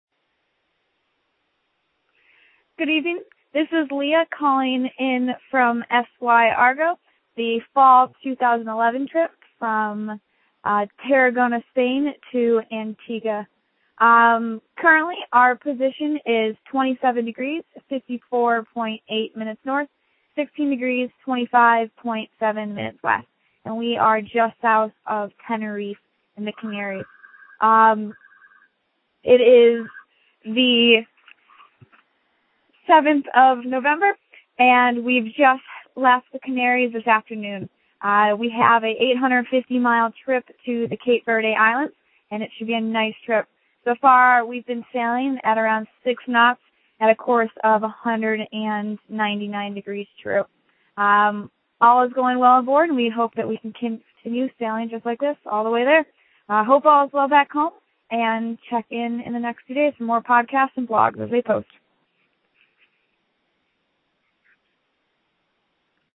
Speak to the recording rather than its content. Click ont the link below to pay the podcast posted by Argo on her first of passage between Tenerife and Cape Verde.